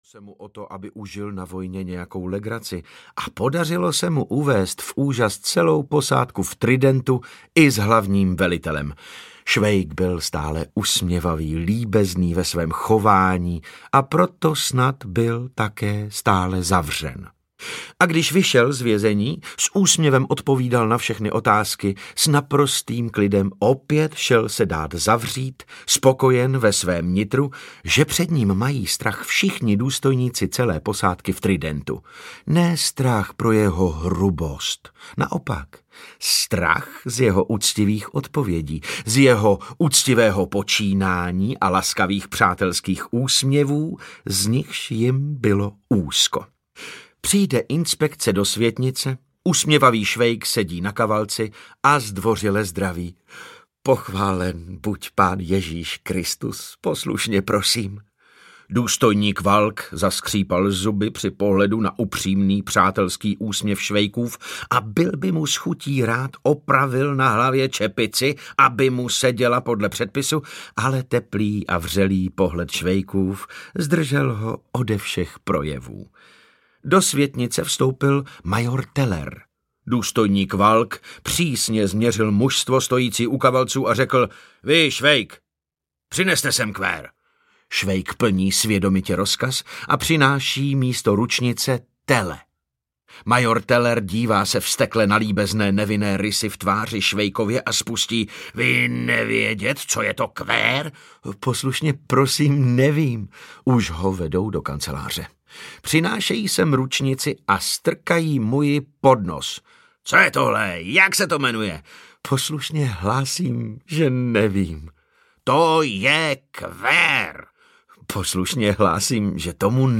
Další osudy dobrého vojáka Švejka audiokniha
Ukázka z knihy
Šest textů o dobrém vojáku Švejkovi z pera Jaroslava Haška, které vznikly ještě před slavným románem. Tyto jiné a překvapivé osudy dobrého vojáka Švejka s gustem načetl herec David Novotný.
• InterpretDavid Novotný